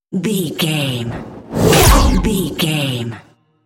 Creature sci fi shot appear
Sound Effects
Atonal
ominous
eerie
whoosh